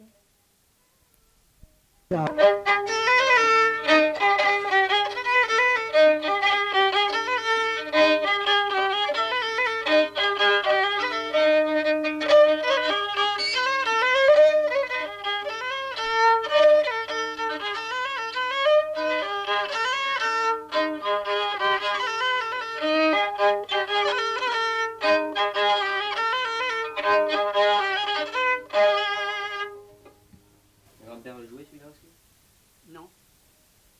Rondeau